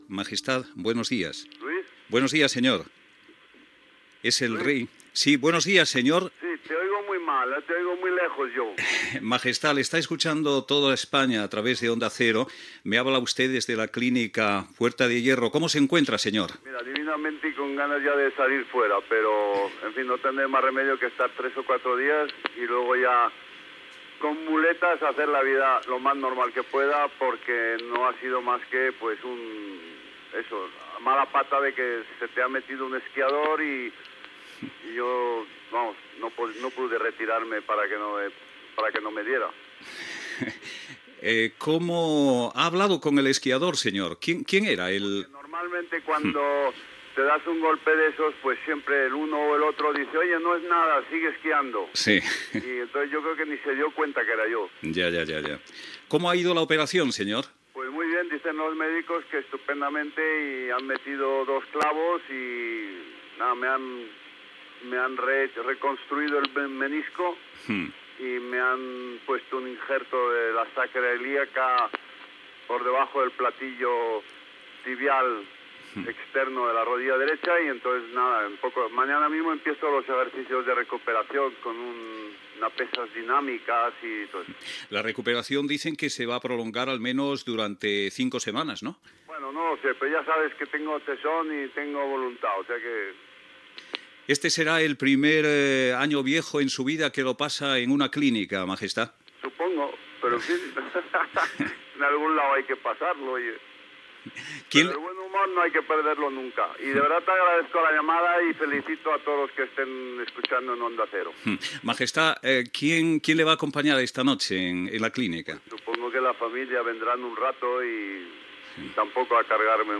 Entrevista al Rei Juan Carlos I, ingressat a la Clínica Puerta de Hierro de Madrid, després de patir un accident esquiant.
Info-entreteniment